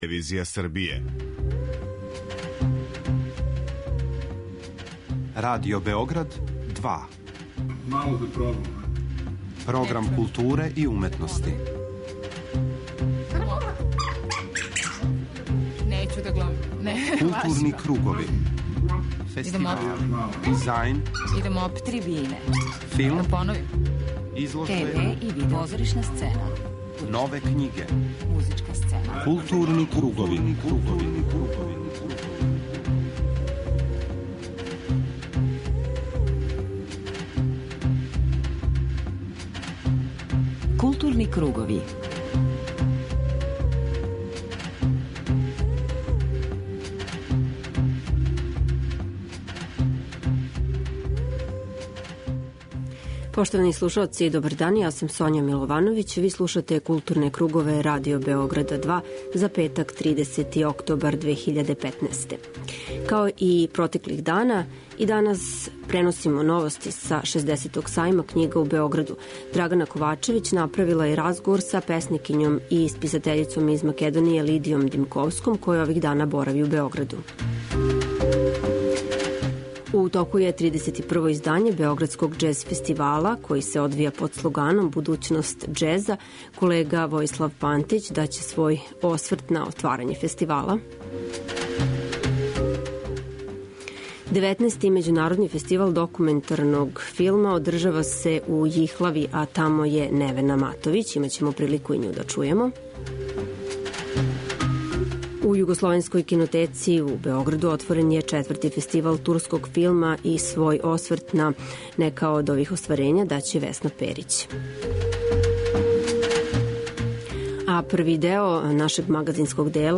У првом сату 'Културних кругова' говоримо о актуелним догађајима из културе, док се 'Гутенбергов одговор', темат посвећен књижевности, емитује уживо са Сајма књига у Београду.